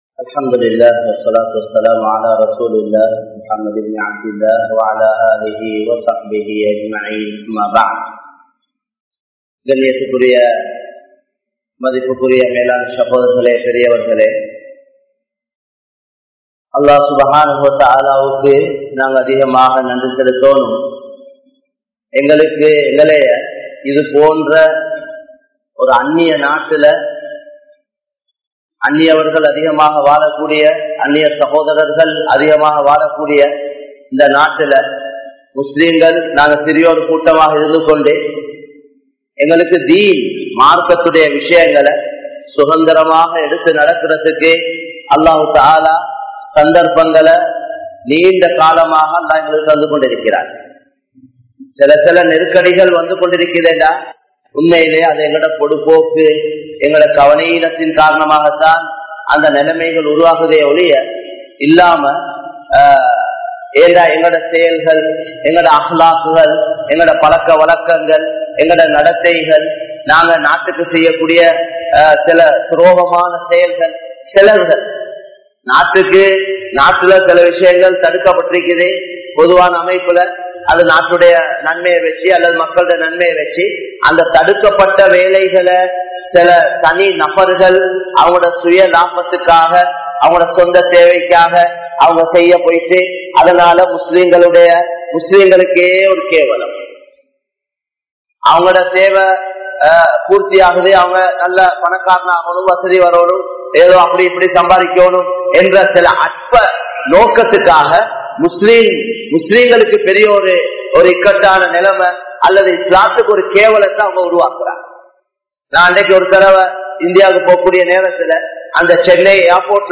Qualities of a Muslim | Audio Bayans | All Ceylon Muslim Youth Community | Addalaichenai
Negombo, Grand Jumua Masjith